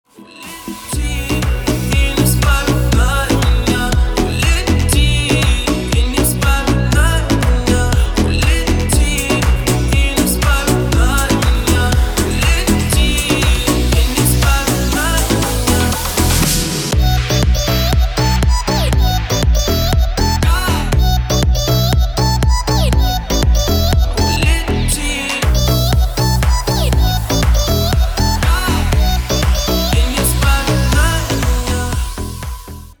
• Качество: 320, Stereo
мужской вокал
deep house
dance
club